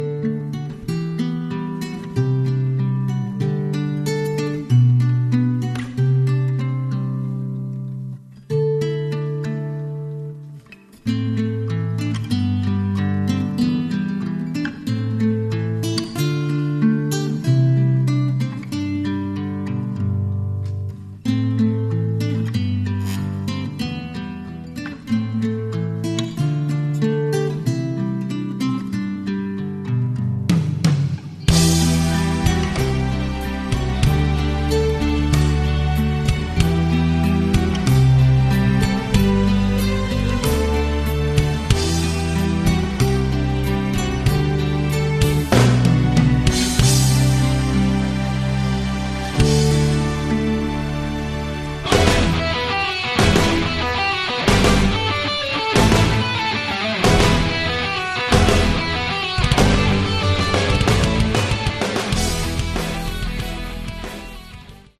Category: Melodic Prog Rock
electric and acoustic guitars
keyboards, backing vocals
drums, backing vocals